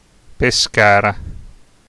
Pescara (Italian: [pesˈkaːra]
It-Pescara.ogg.mp3